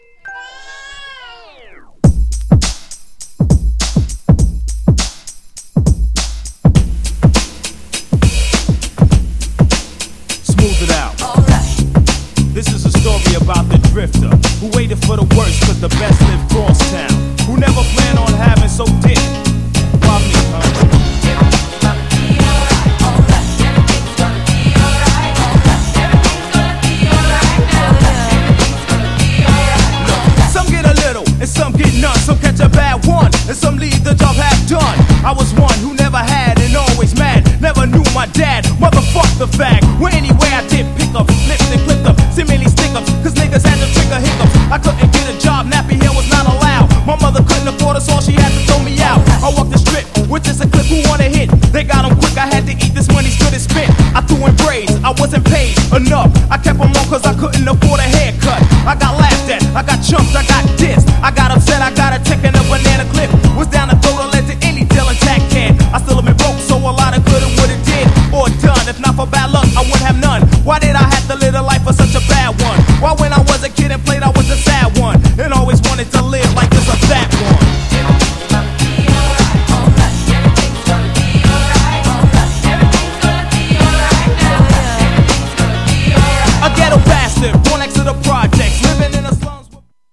GENRE Hip Hop
BPM 96〜100BPM